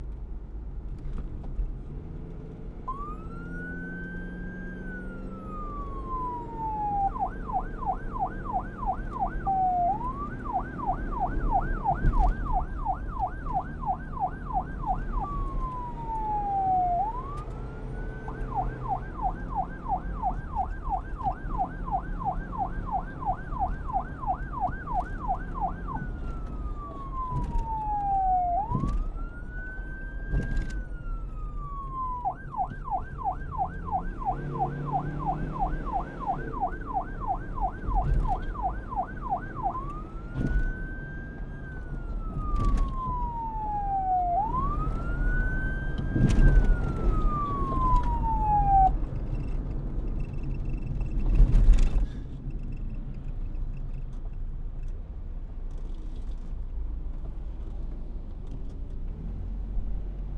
Sound Effect
Police Siren
Police_Siren.mp3